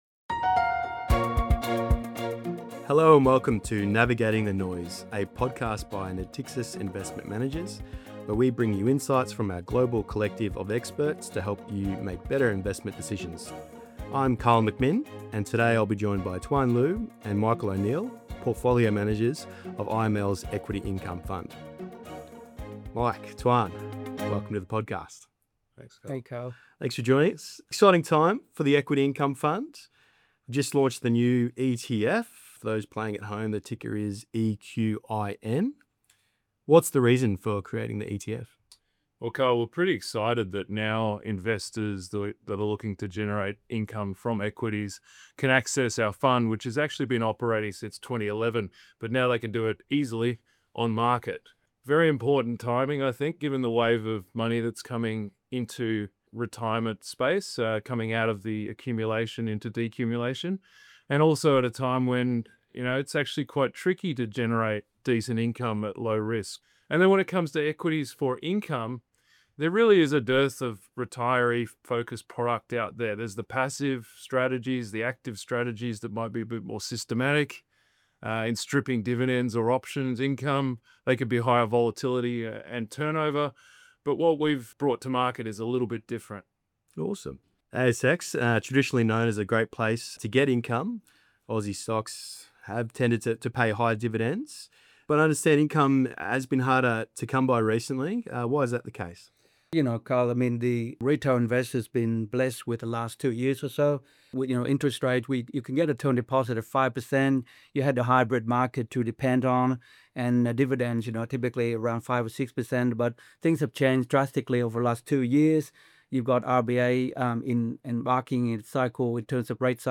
Lightly edited transcript – Recorded on 2 September, 2025